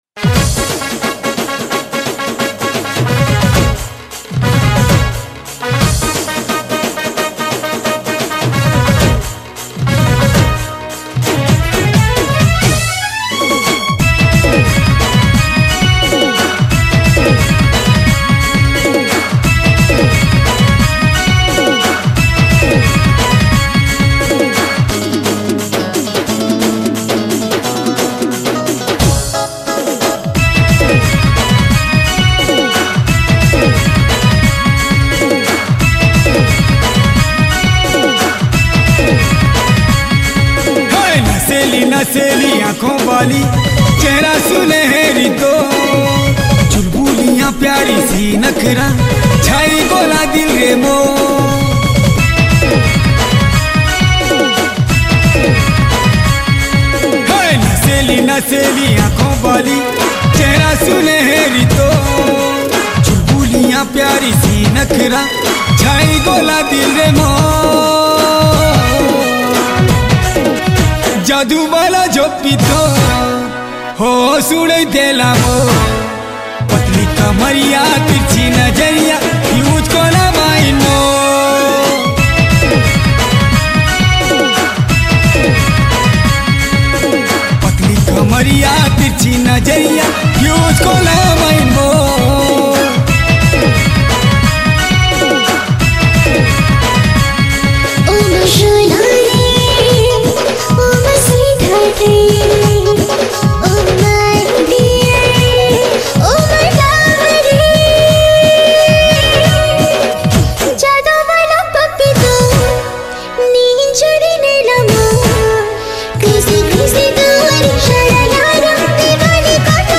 Sambapuri Single Song 2022